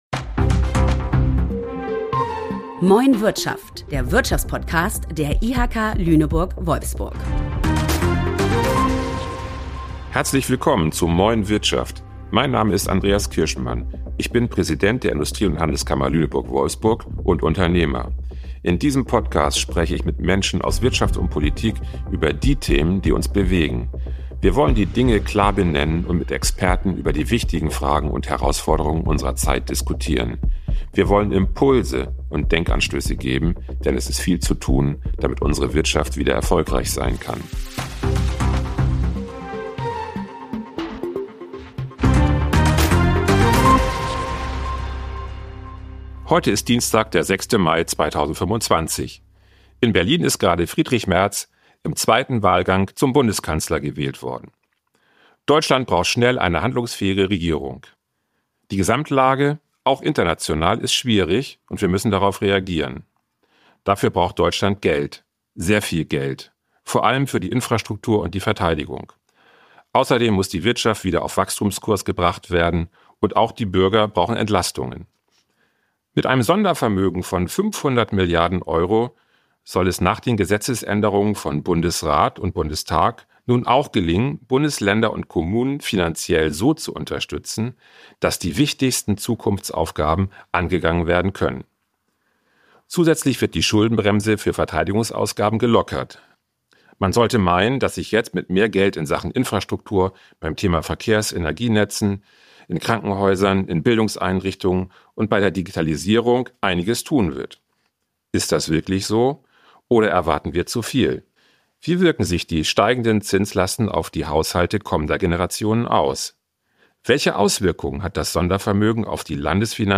Um das zu besprechen, sind in dieser Folge der Finanzminister Niedersachsens Gerald Heere und der Harburger Landrat Rainer Rempe zu Gast.